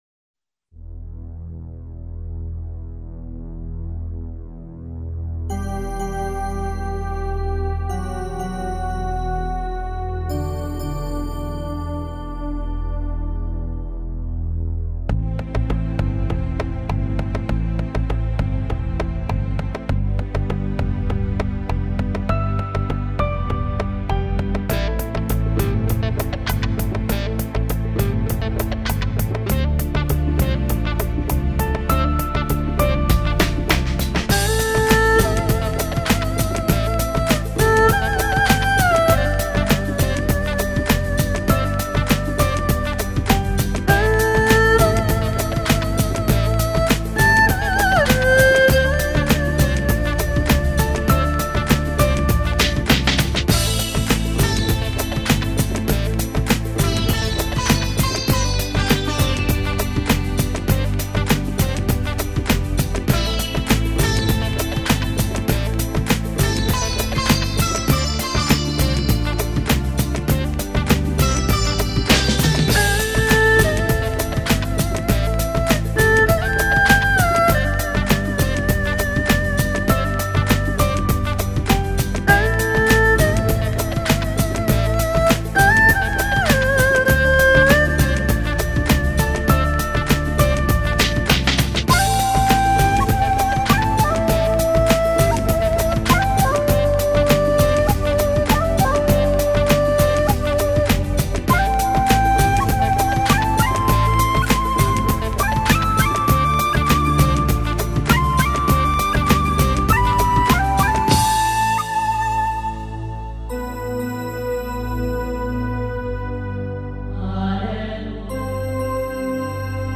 有些英格玛的味道，很有创意。